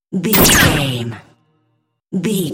Dramatic stab laser shot energy
Sound Effects
heavy
intense
dark
aggressive
hits